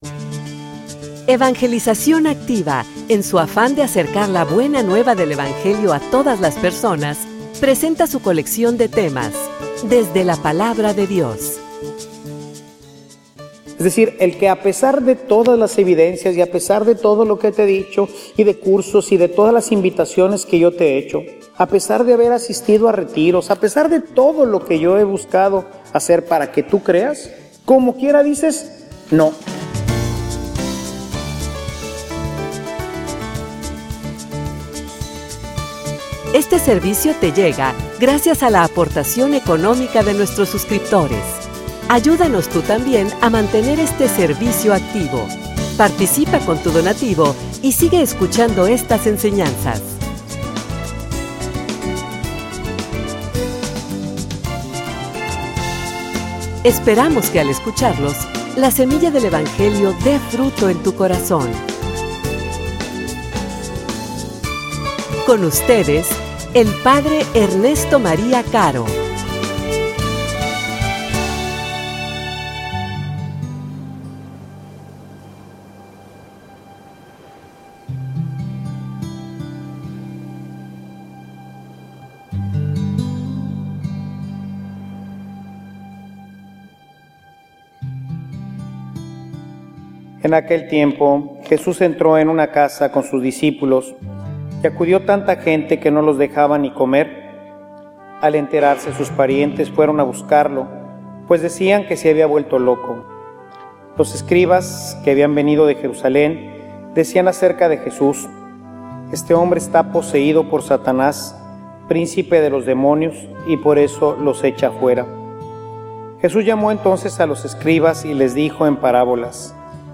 homilia_No_seas_irracional.mp3